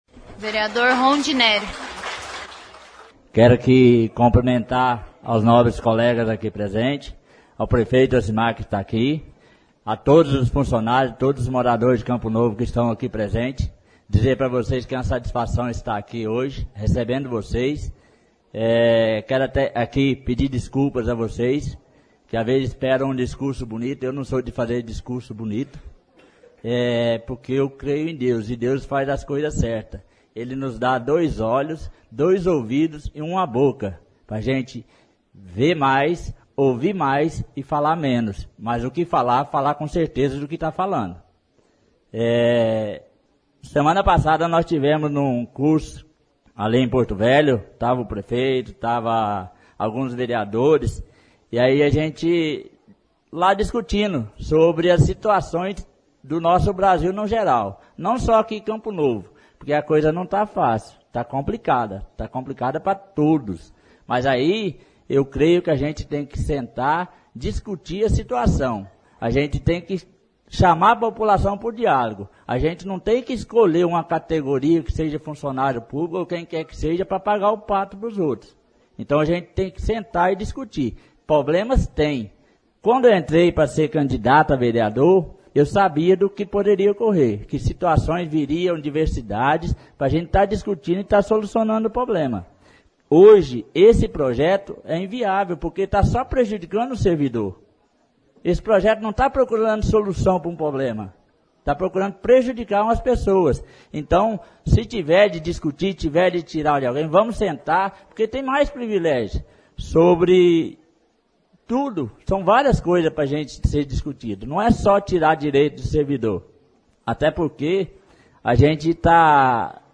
63ª Sessão Ordinária